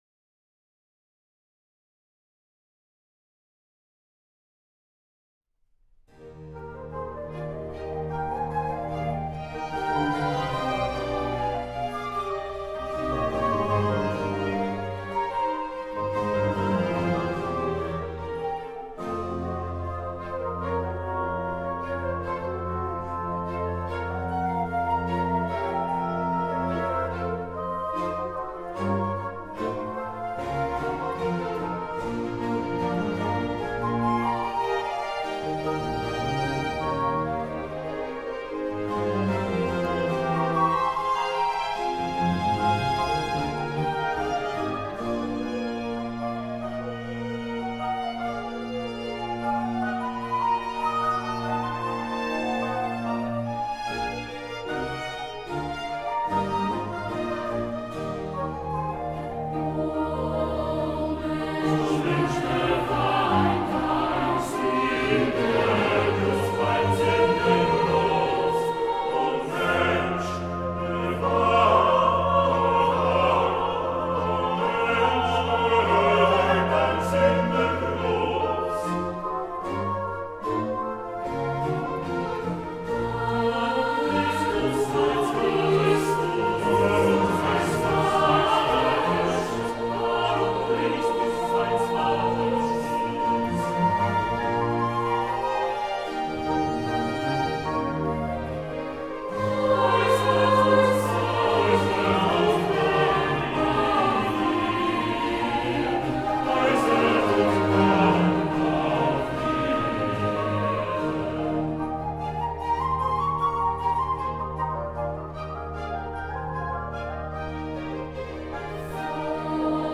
Choral